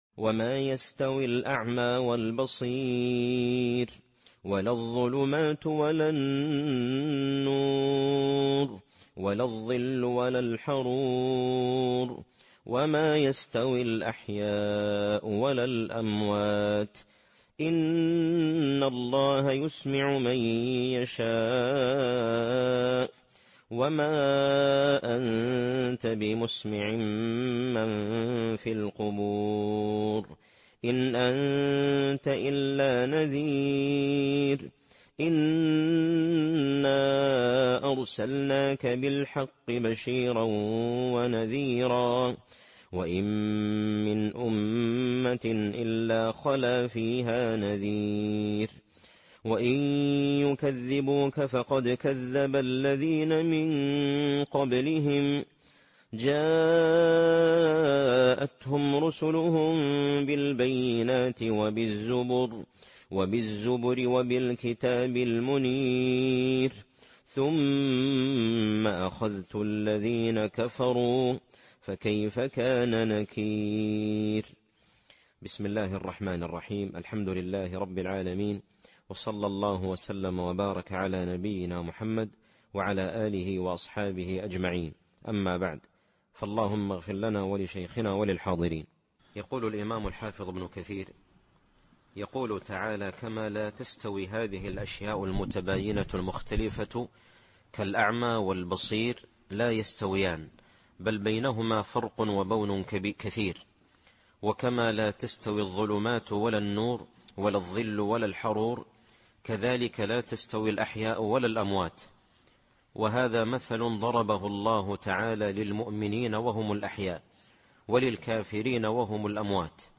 التفسير الصوتي [فاطر / 19]